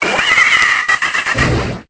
Cri de Malamandre dans Pokémon Épée et Bouclier.